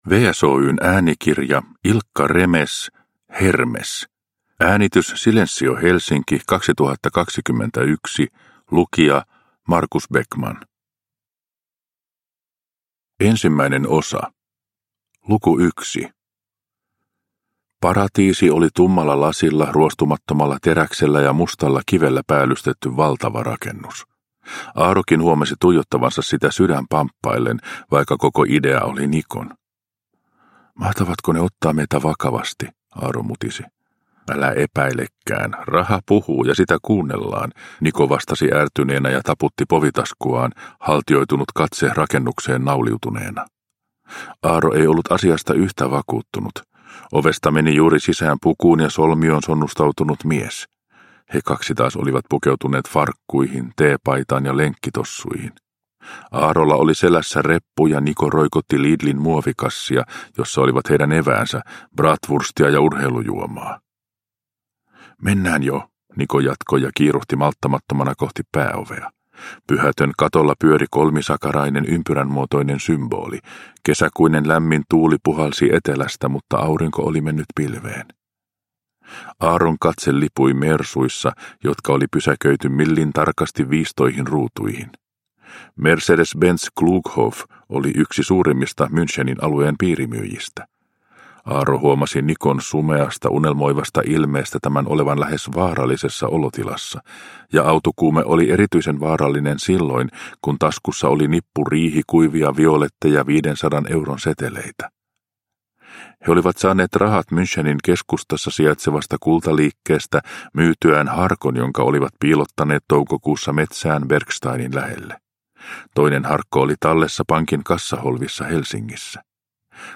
Hermes – Ljudbok – Laddas ner